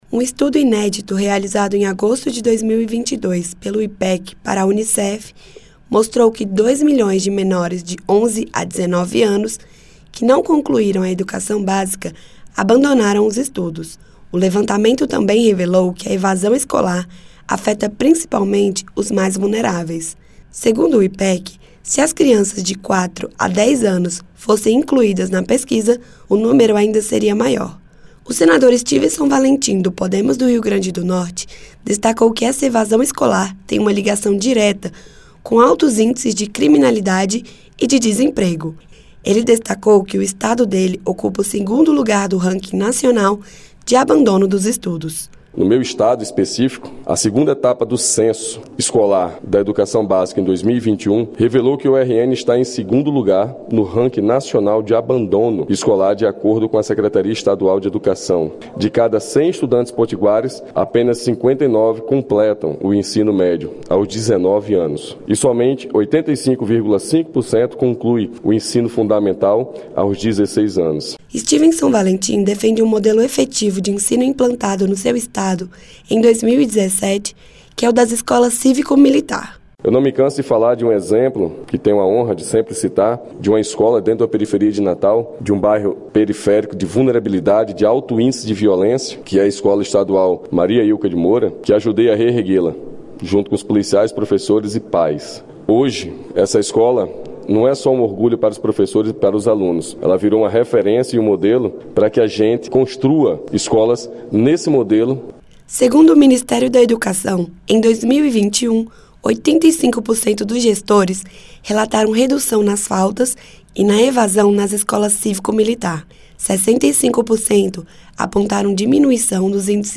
Em sessão no Plenário, o senador Styvenson Valentim (PODEMOS-RN) alertou para os altos índices de evasão escolar no Brasil e defendeu um modelo de escola cívico-militar que, segundo ele, no Rio Grande do Norte obteve resultados positivos.